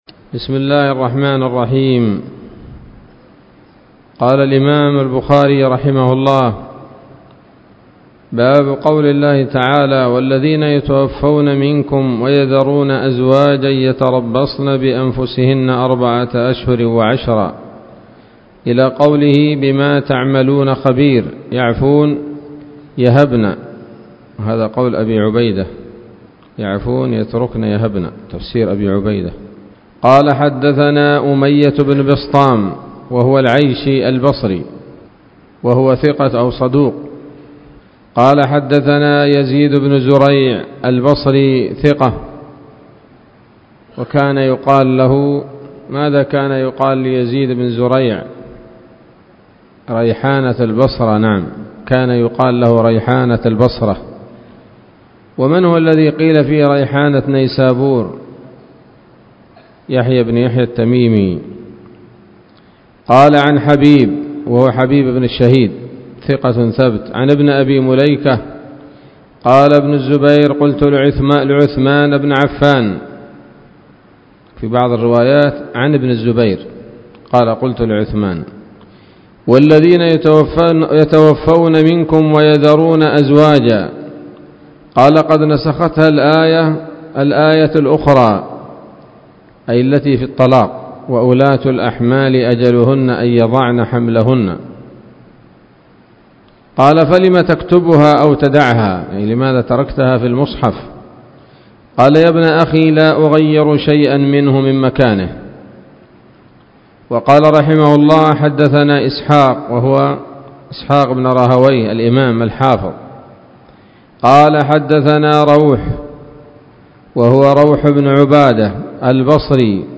الدرس الرابع والثلاثون من كتاب التفسير من صحيح الإمام البخاري